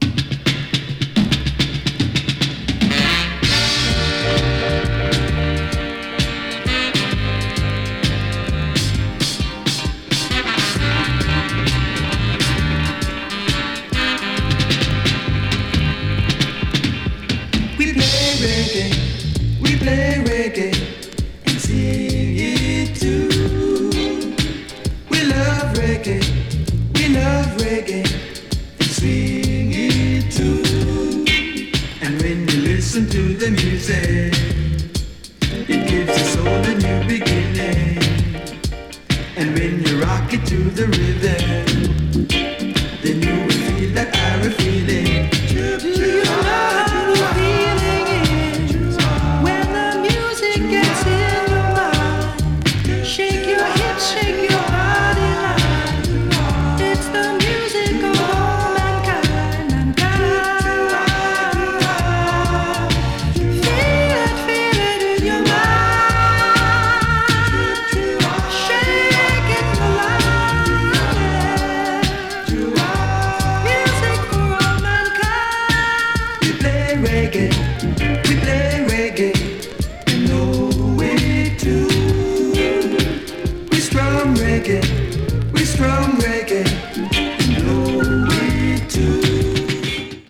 ジャマイカのバンドながらUKレゲエ的なサウンドが特徴です！
70s FUNKY REGGAE / DUB 踊る 詳細を表示する